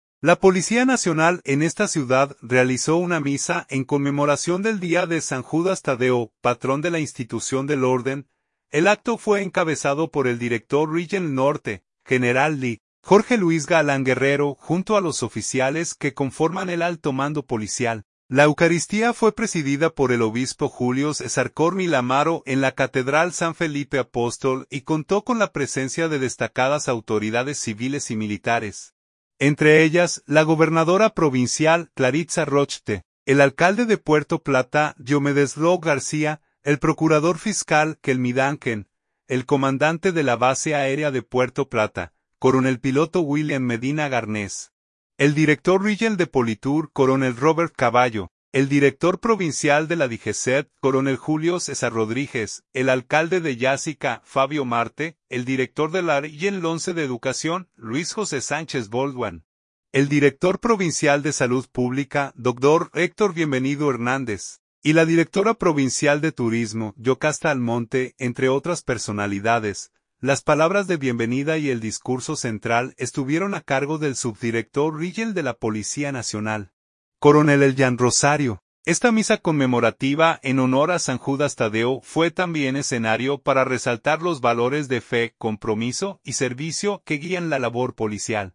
Policía Nacional realiza misa en conmemoración de San Judas Tadeo en Puerto Plata
La eucaristía fue presidida por el obispo Julio César Corniel Amaro en la Catedral San Felipe Apóstol y contó con la presencia de destacadas autoridades civiles y militares, entre ellas: la gobernadora provincial, Claritza Rochtte; el alcalde de Puerto Plata, Diomedes Roque García; el procurador fiscal, Kelmi Duncan; el comandante de la Base Aérea de Puerto Plata, coronel piloto William Medina Garnés; el director regional de POLITUR, coronel Robert Cavallo; el director provincial de la DIGESETT, coronel Julio César Rodríguez; el alcalde de Yásica, Fabio Marte; el director de la Regional 11 de Educación, Luis José Sánchez Baldwin; el director provincial de Salud Pública, Dr. Héctor Bienvenido Hernández; y la directora provincial de Turismo, Yokasta Almonte, entre otras personalidades.